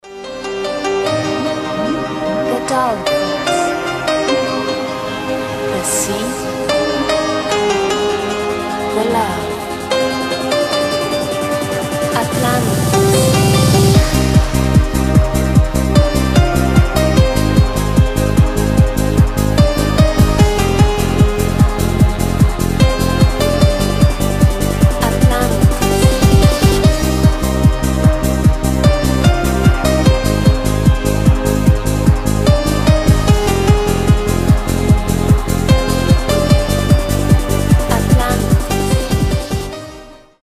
Танцевальные рингтоны
Рингтоны техно , Евродэнс
Транс